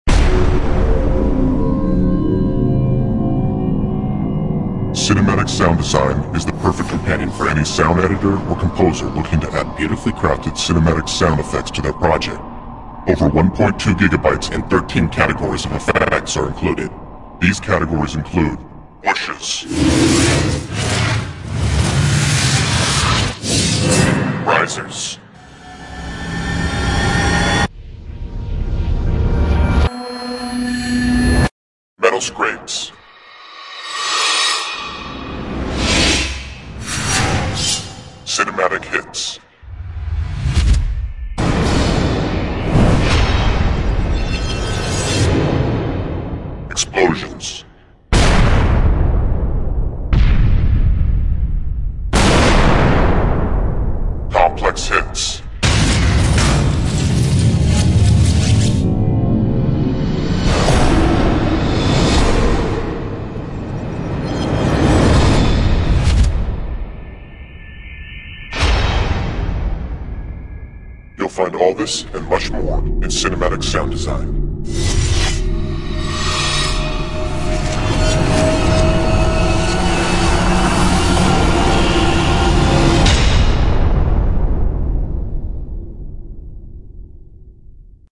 音效-307种好莱坞大气史诗飞快移动打击爆炸上升音效
Cinematic Sound Design具有13个类别中超过1.2 GB的好莱坞声音设计元素，这些元素是专门为电影和电视音乐的作曲家和制作人创建和编译的。